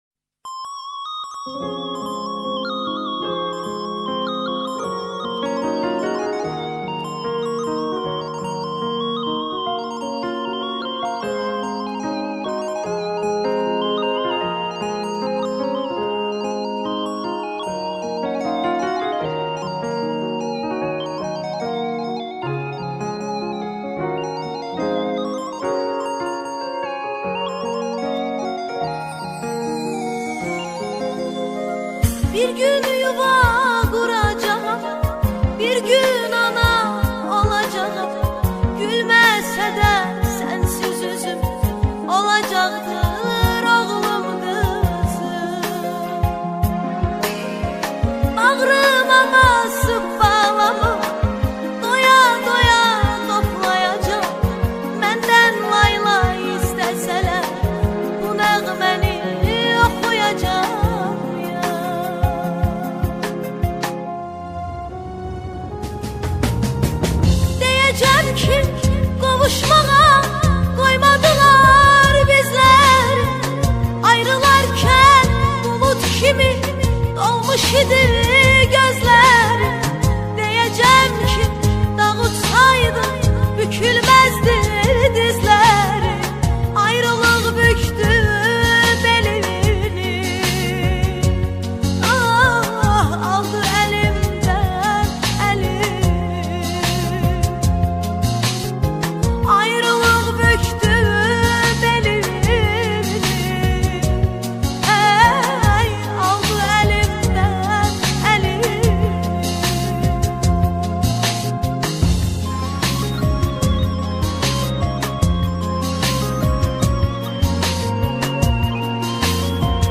آهنگ آذربایجانی آهنگ غمگین آذربایجانی